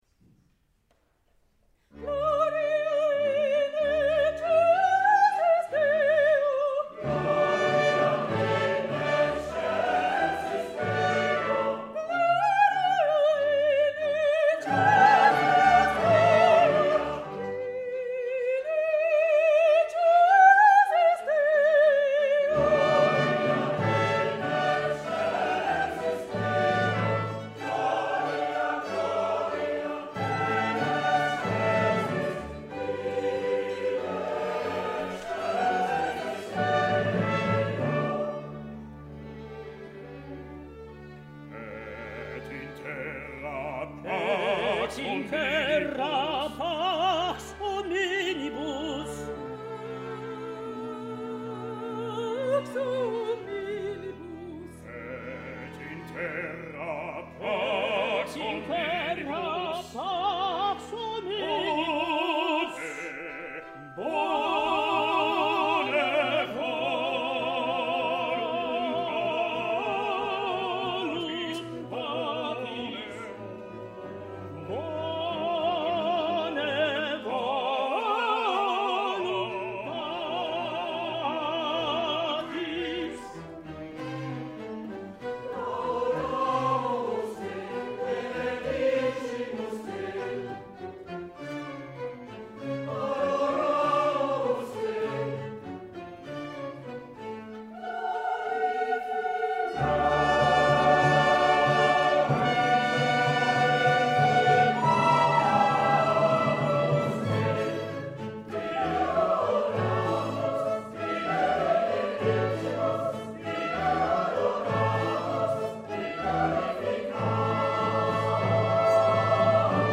Concert de l’Avent (Gloria)